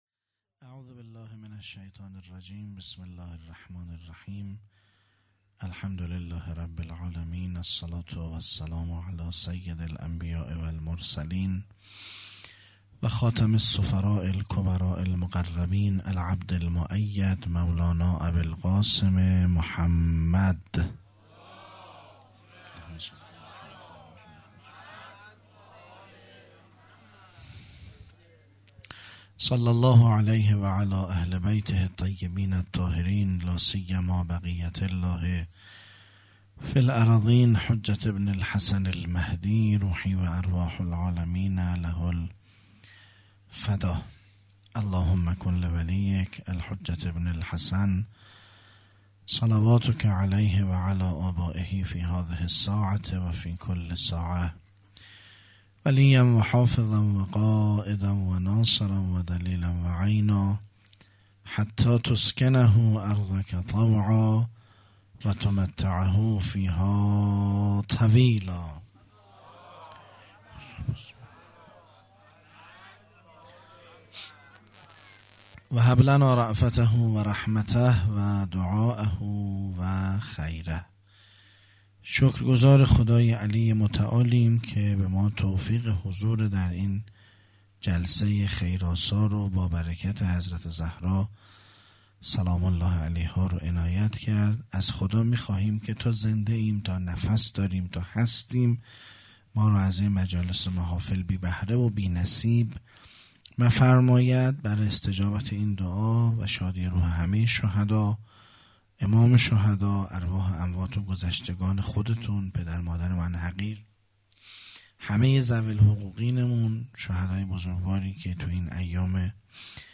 فاطمیه دوم ۱۴۰۱_شب سوم